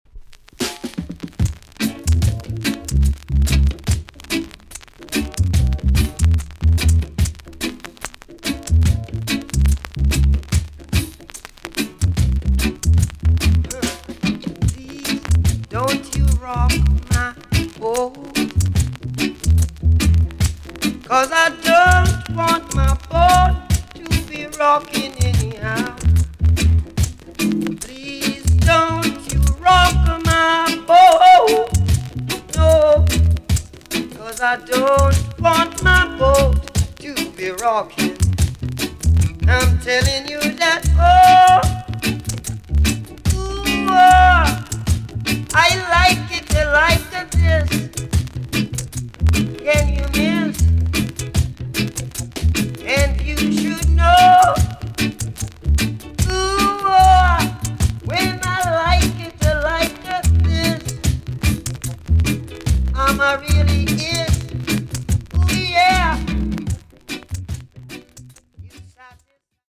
TOP >LP >VINTAGE , OLDIES , REGGAE
B.SIDE VG+ 少し軽いチリノイズが入ります。